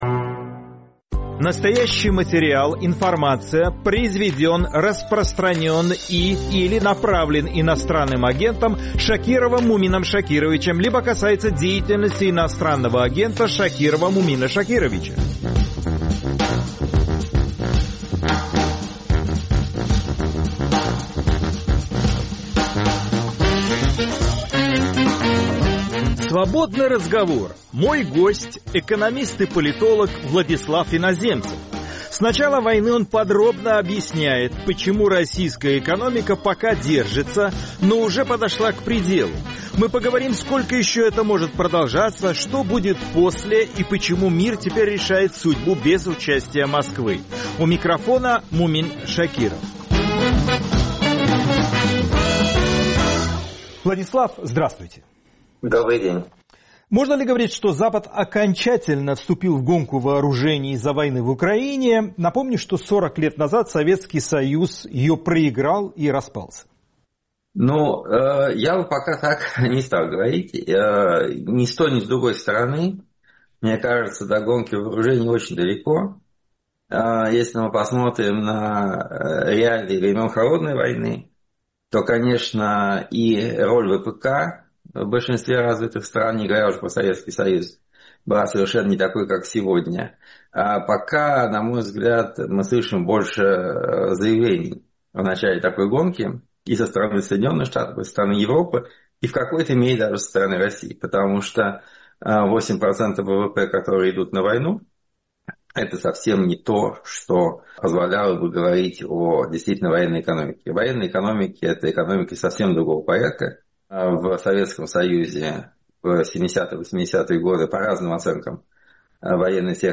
Свободный разговор с экономистом Владиславом Иноземцевым